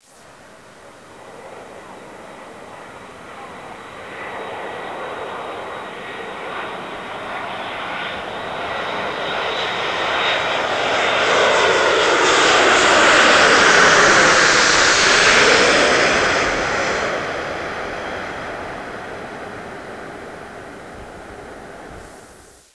Real Audio 23 seconds, aiff 532k) of aircraft overhead.
turbine.aif